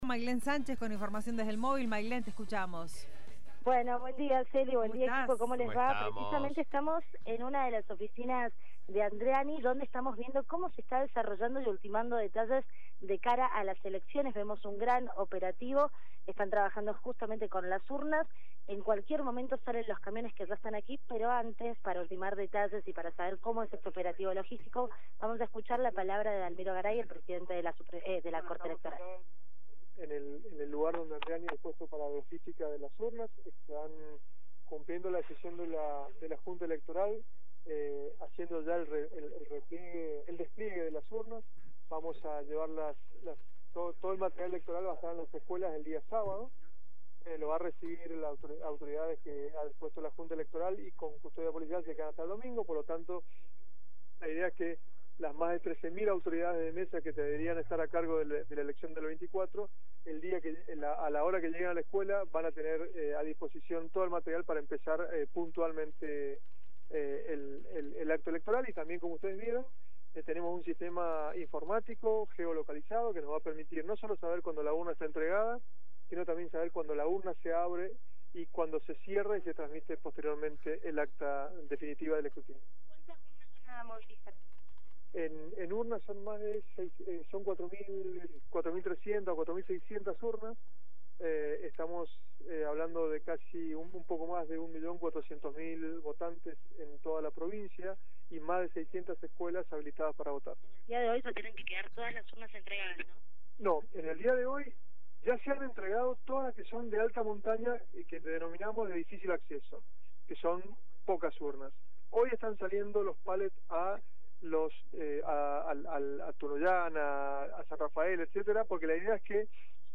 LVDiez - Radio de Cuyo - Móvil de LVDiez- Dalmiro Garay, Pte Junta Electoral en oficinas Correo Andreani